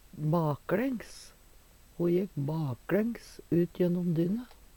baklængs - Numedalsmål (en-US)